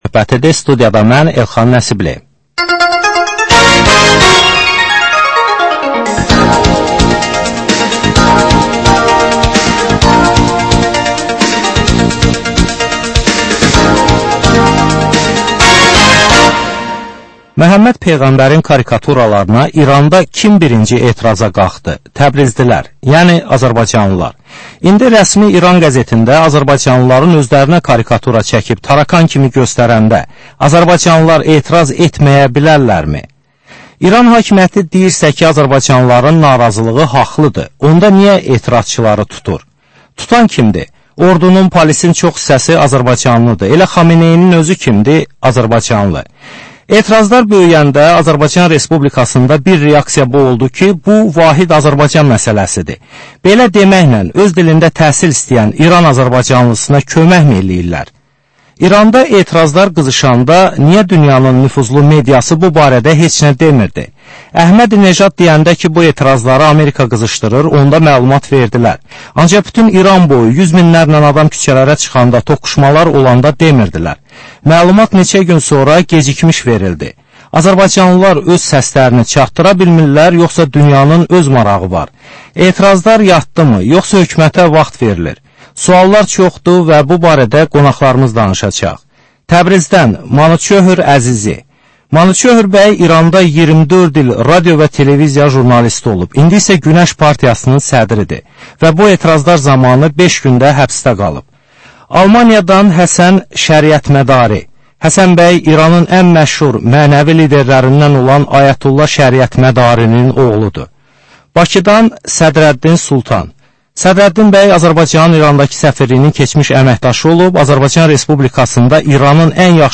Aktual mövzu barədə canlı dəyirmi masa söhbəti.